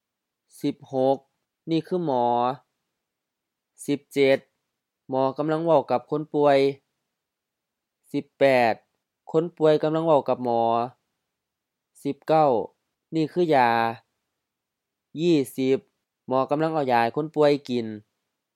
หมอ mɔ: M หมอ 1. doctor, physician
คนป่วย khon-pu:ai HR-H คนป่วย patient, sick person
ยา ya: M ยา 1. medicine